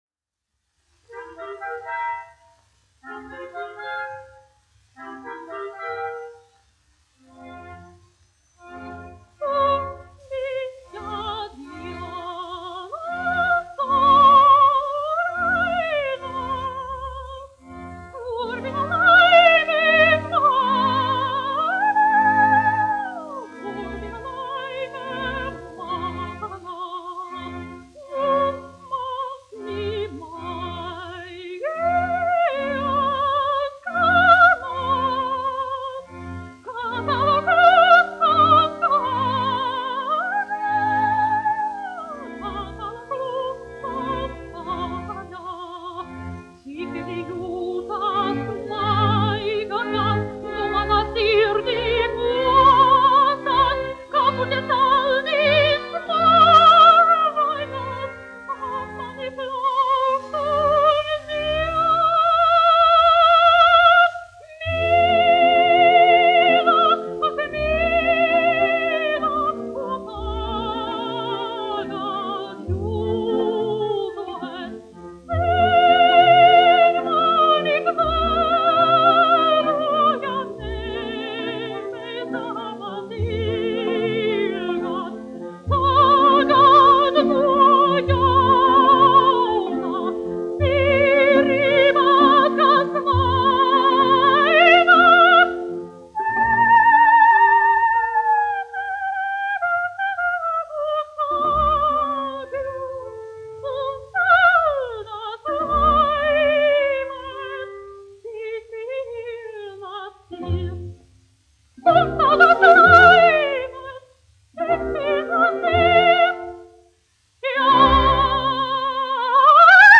Latvijas Nacionālās operas orķestris, izpildītājs
1 skpl. : analogs, 78 apgr/min, mono ; 25 cm
Operas--Fragmenti
Skaņuplate